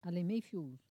Localisation Saint-Jean-de-Monts
Catégorie Locution